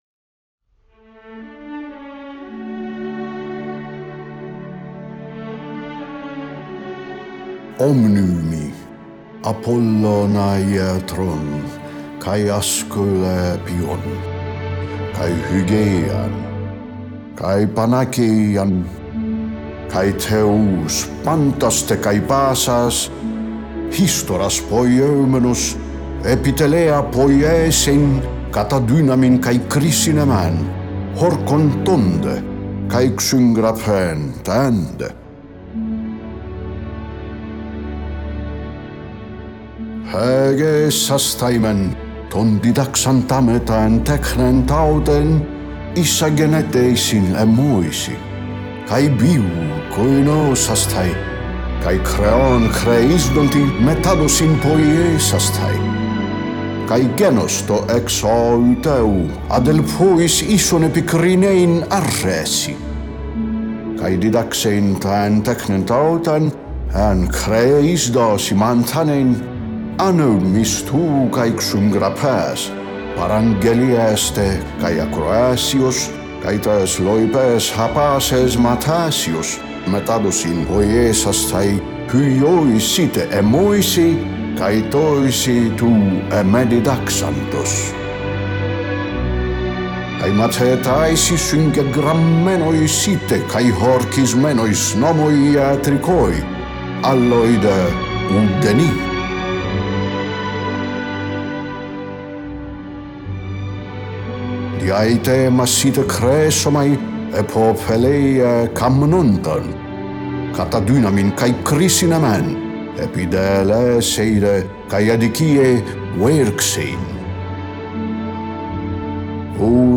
Unabridged
You can follow the traditional "Medical Oath" of Hippocrates as it came down to us, recited in ancient Greek in reconstructed pronunciation.